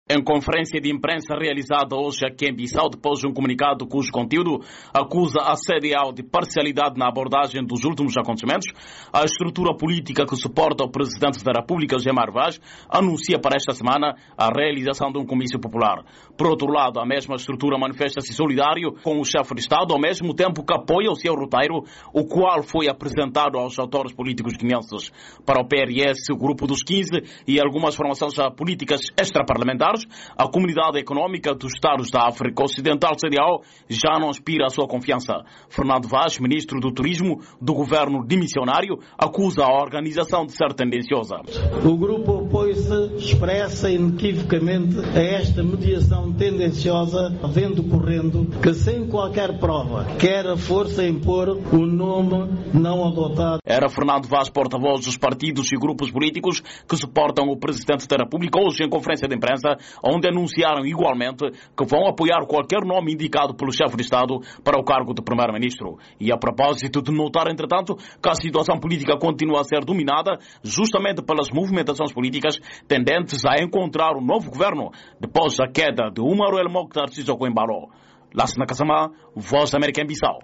Em conferência de imprensa realizada nesta segunda-feira, 22, em Bissau, a estrutura política que suporta o Presidente da Republica, José Mário Vaz, anuncia, para esta semana, a realização de um comício popular, ao mesmo que manifesta-se solidário com o Chefe de Estado.